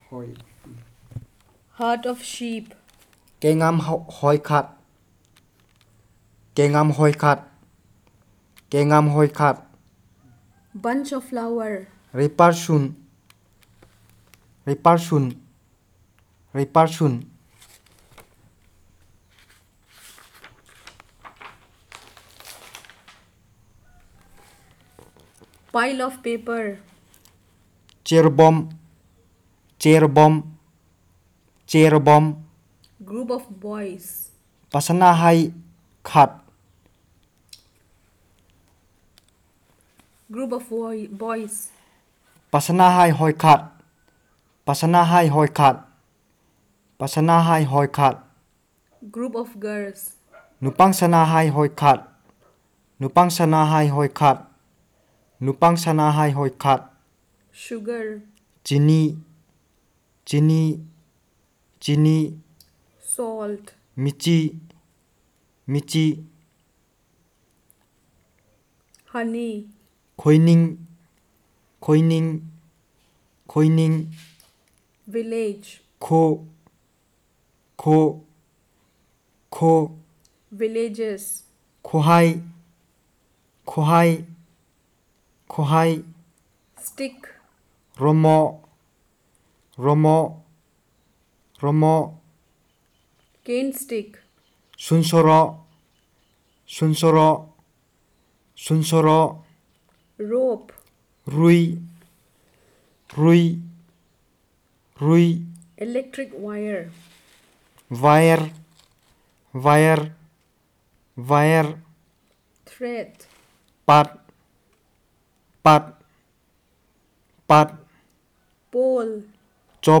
Elicitation of words about number, gender and classifiers